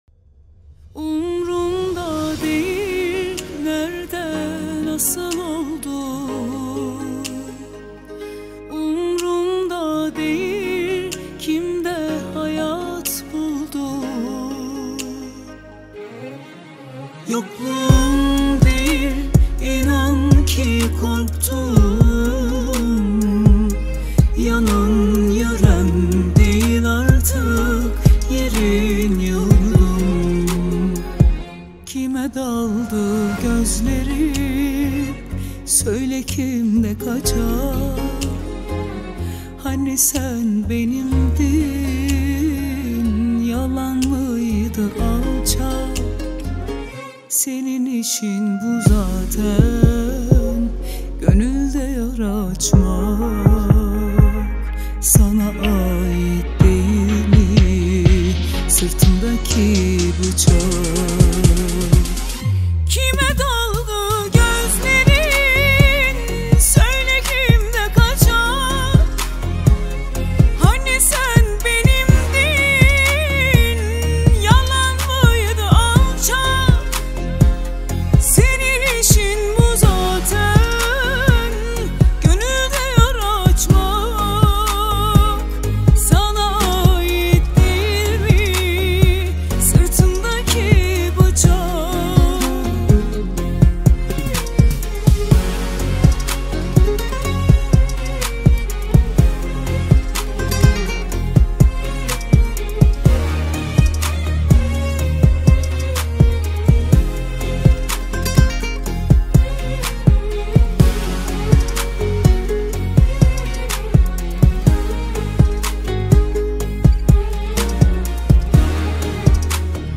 Узбекские песни Слушали